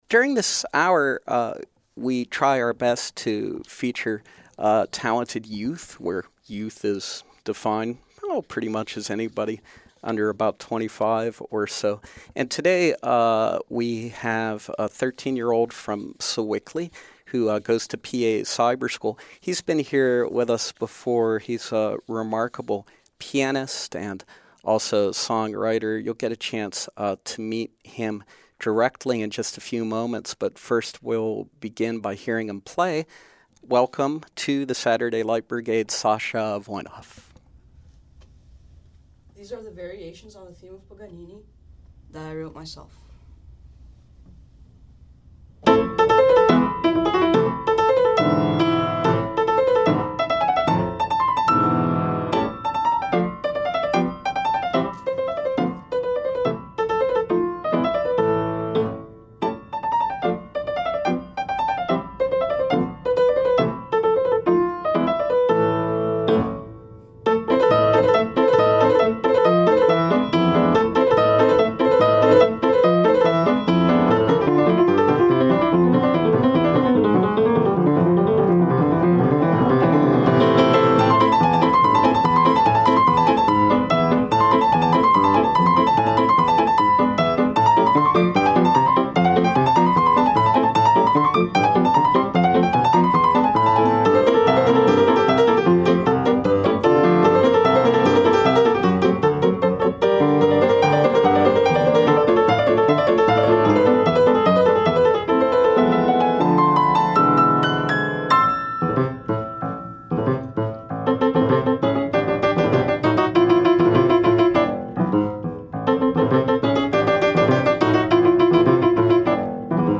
the very talented, young pianist and songwriter
live in our studios.